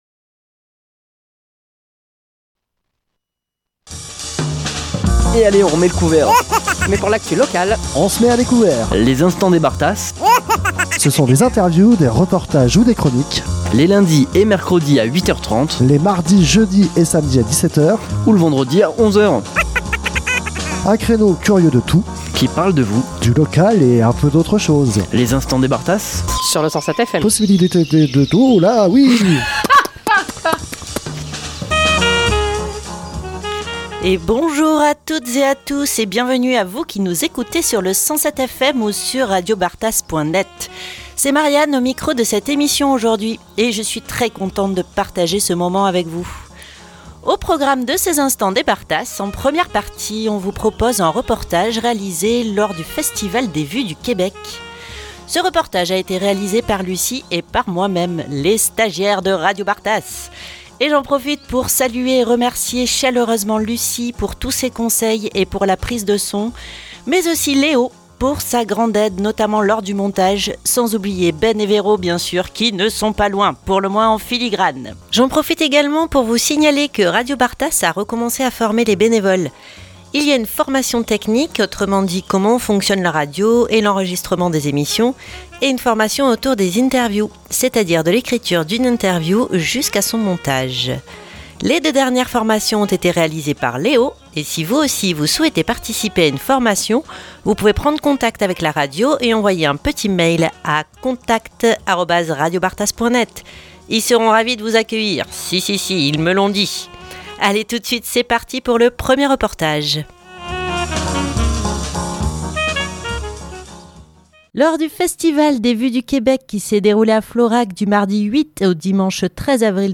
Canot Bitume et interviews réalisé par les bénévoles au programme aujourd'hui.